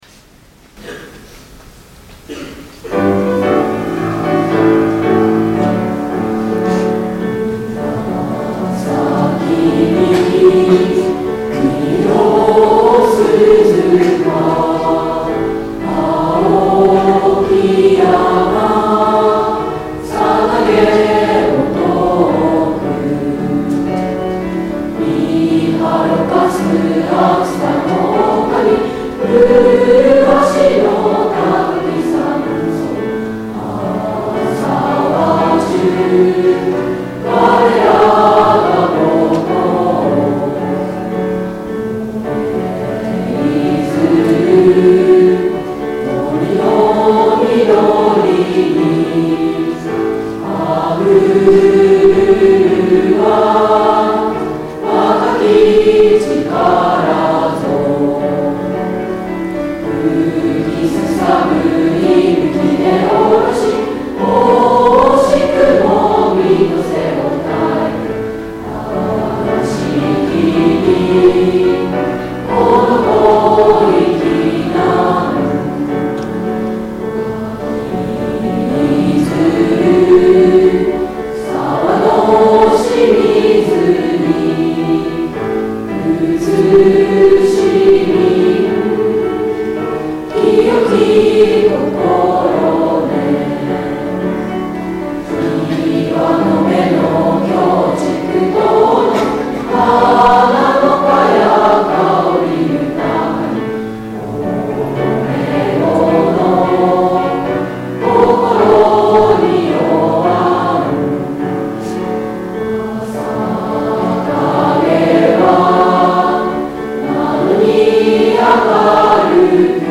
校歌再生